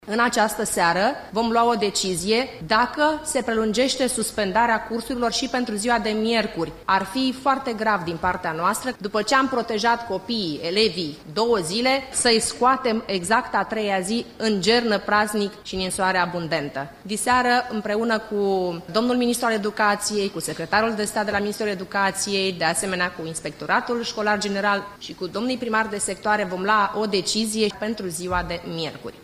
În București, nu este exclus ca și miercuri să fie suspendate cursurile – din cauza gerului și a faptului că de mâine seară revin ninsorile abundente. Primarul general, Gabriela Firea, a  declarat că o decizie va fi luată după discuțiile de luni seară cu ministrul Educației și cu șefii inspectoratelor școlare: